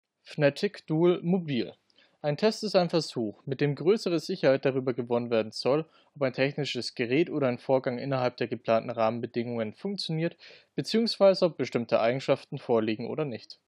Fnatic Gear Duel In-Line „mobil“ Mikrofon:
In beiden Fällen ist der gesprochene Text verständlich zu hören. Während das Boom Mikrofon (langes Kabel) etwas lauter ausfiel, fällt die Aufnahme im Vergleich zu dem der mobilen Variante flacher aus.